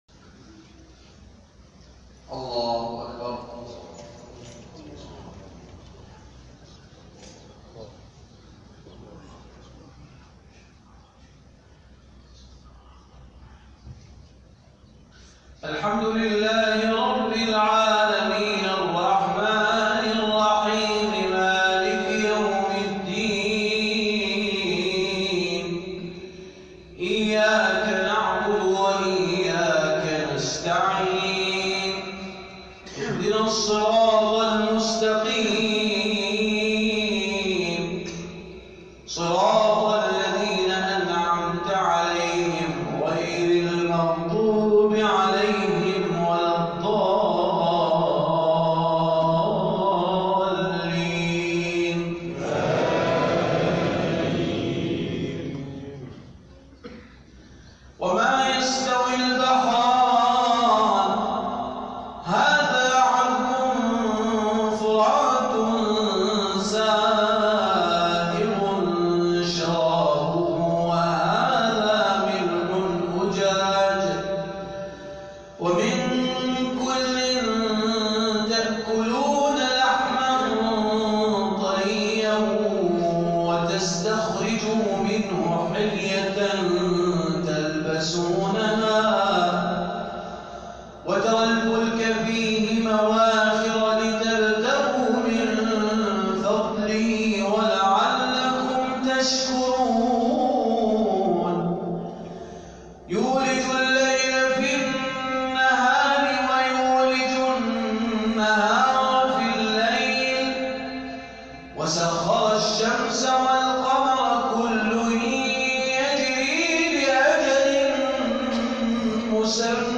عشائية
بالمقام العراقي